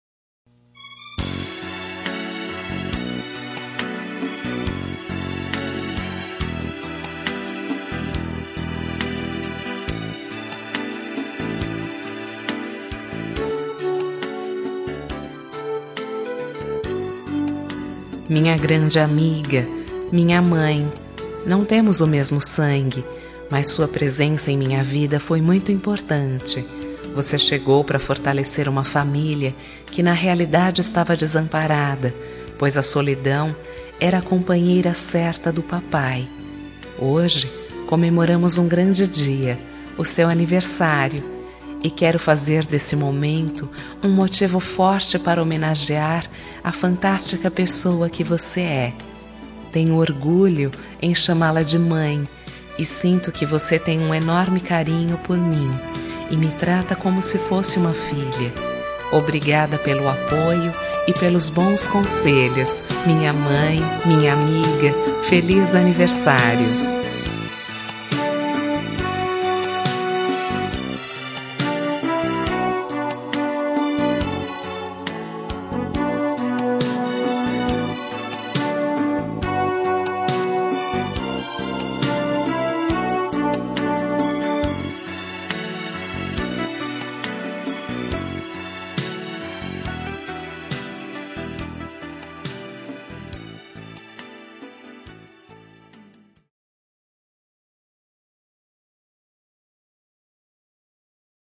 Telemensagem Aniversário de Mãe – Voz Feminina – Cód: 1408 – Madrasta